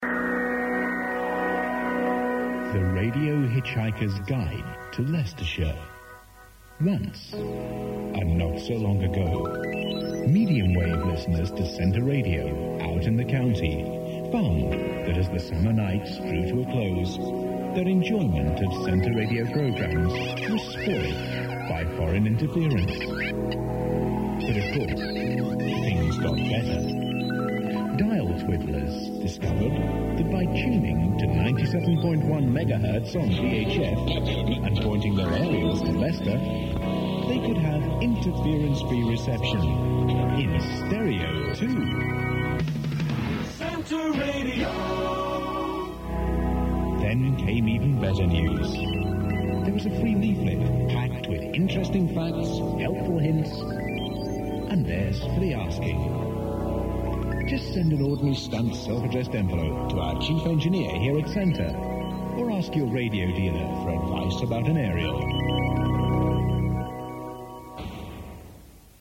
A promo from Centre Radio in Leicester advising listeners of the better reception on 97.1 FM